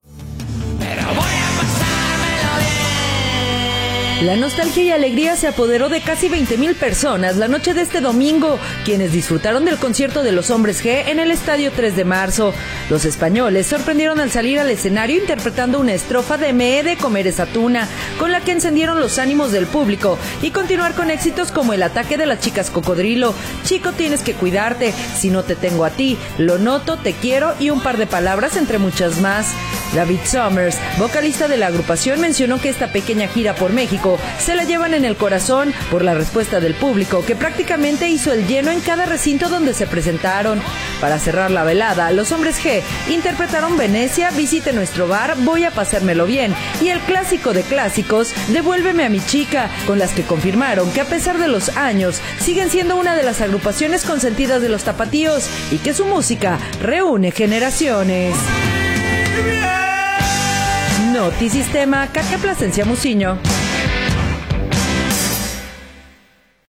La nostalgia y alegría se apoderó de casi 20 mil personas la noche de este domingo quienes disfrutaron del concierto de los Hombres G en el Estadio 3 de marzo.
hombresg_mezcla.m4a